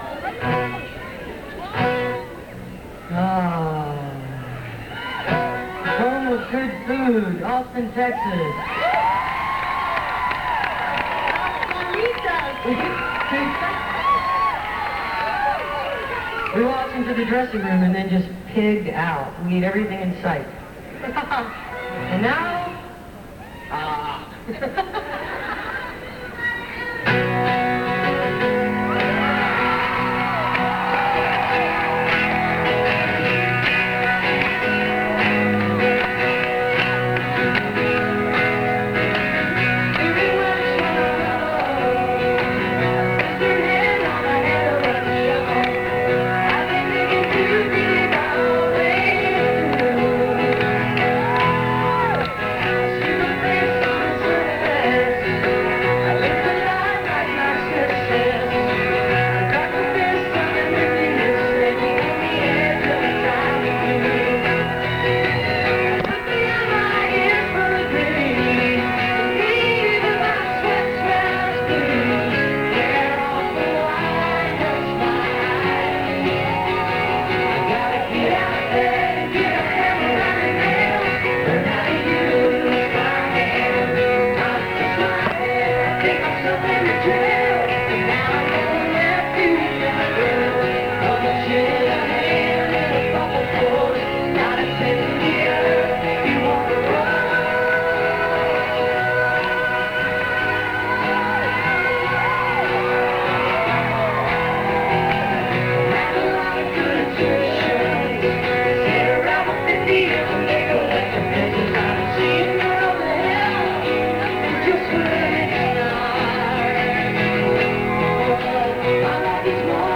(incomplete, band show)